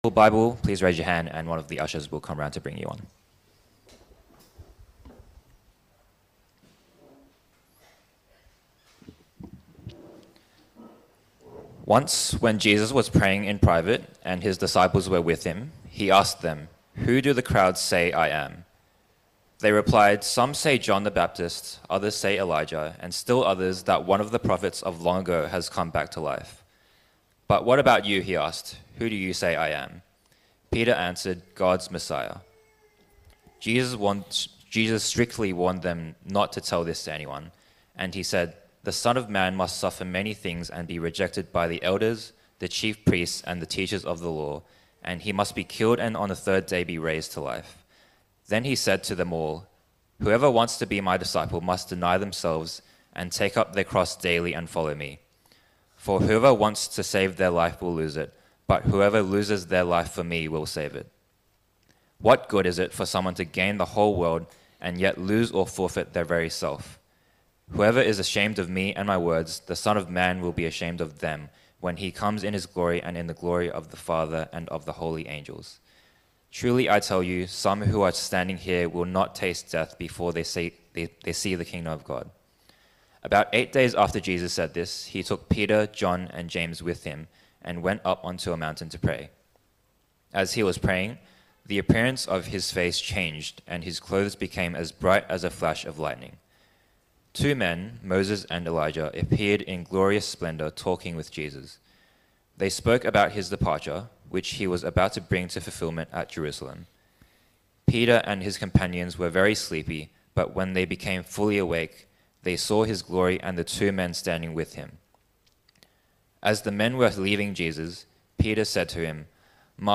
Jesus’ identity and glory Preacher: